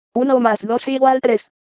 Spanish Text to Speech Voices - MWS Reader
Lernout & Hauspie® TTS3000 TTS engine – Spanish